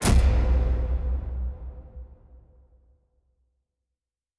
Sci-Fi Sounds / Interface / Complex Interface 4.wav
Complex Interface 4.wav